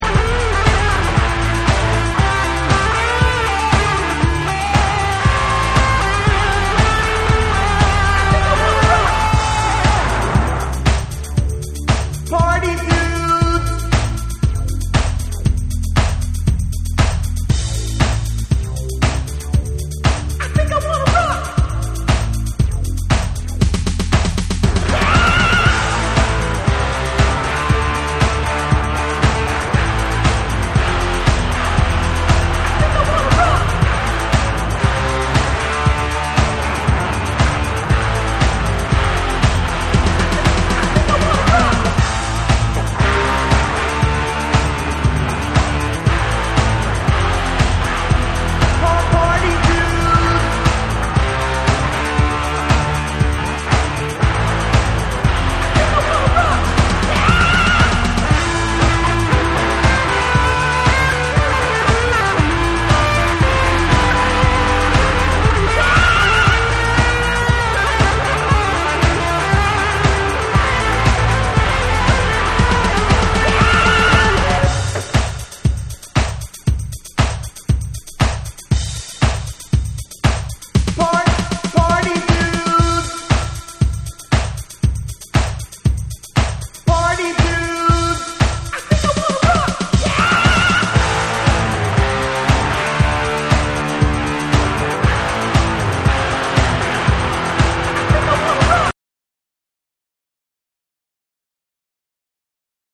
TECHNO & HOUSE / BACK TO BASIC